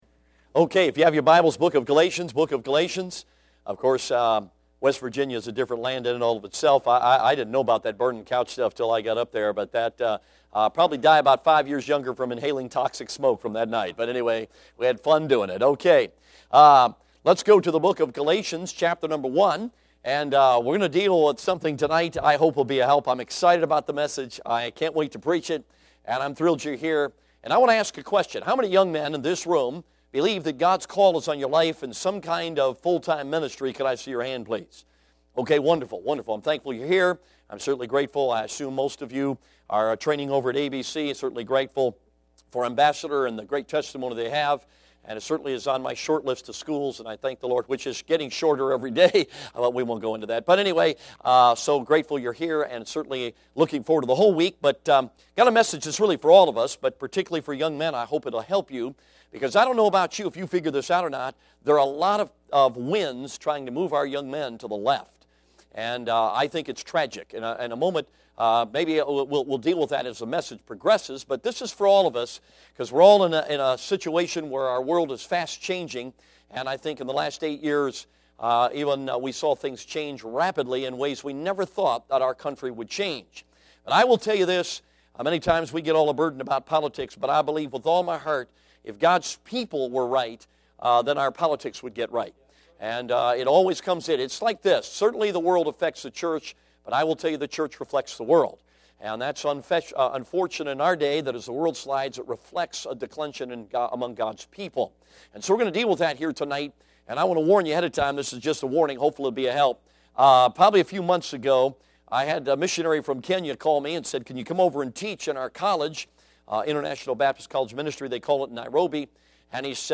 Passage: Galatians Service Type: Sunday PM Bible Text